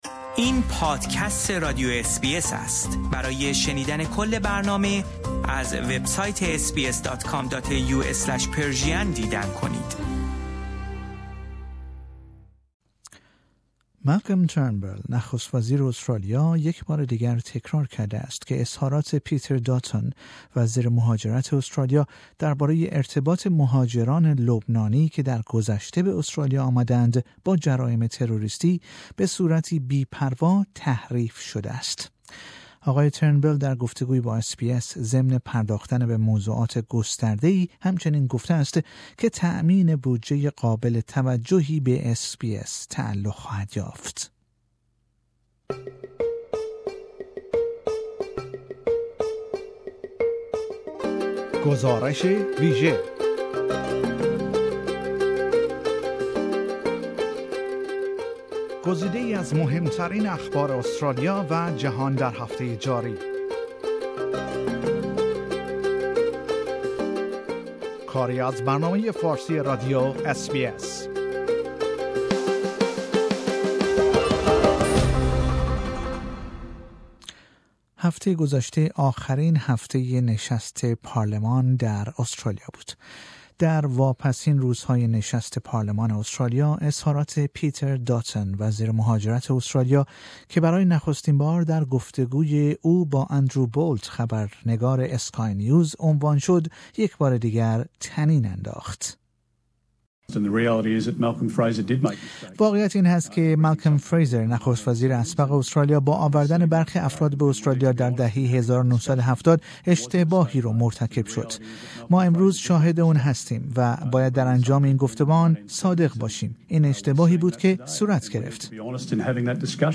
PM Malcolm Turnbull gives wide ranging interview to SBS